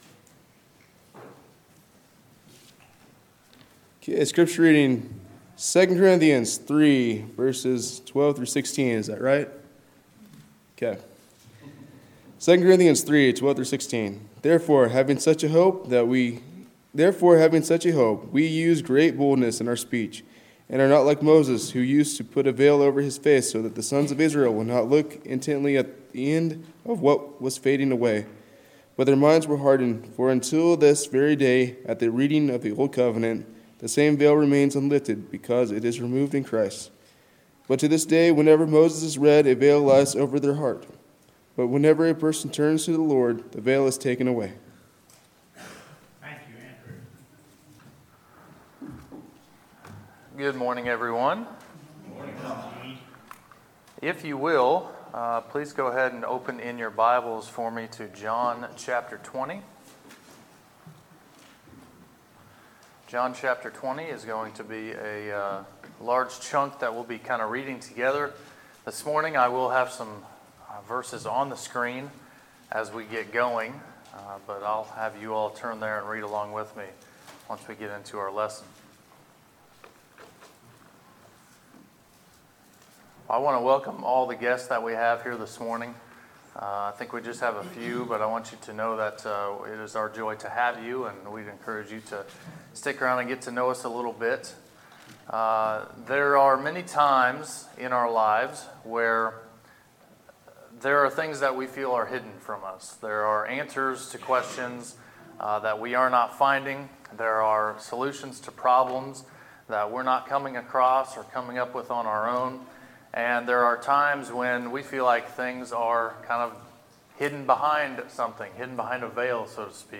Sermons, July 7, 2019